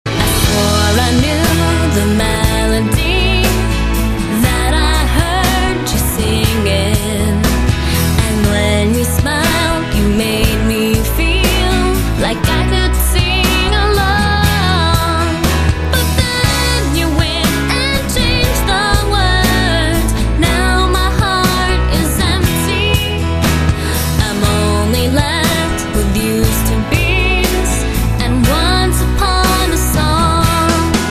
M4R铃声, MP3铃声, 欧美歌曲 49 首发日期：2018-05-15 22:01 星期二